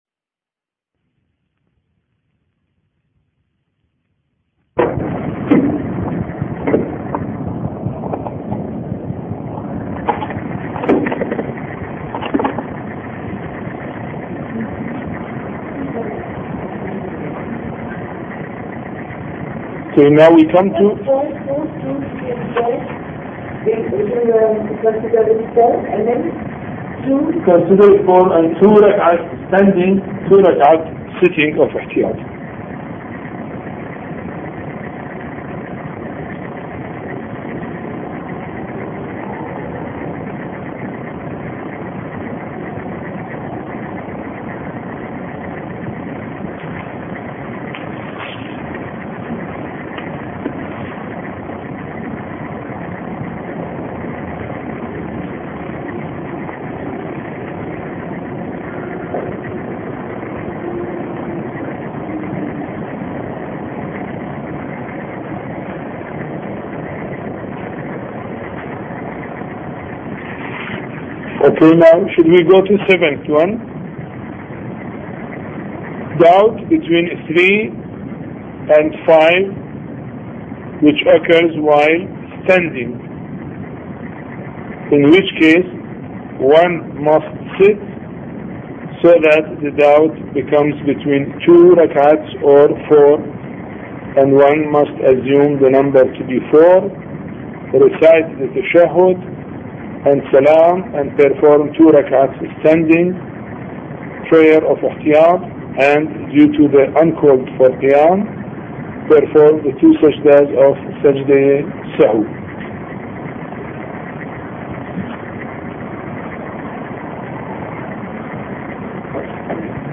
A Course on Fiqh Lecture 21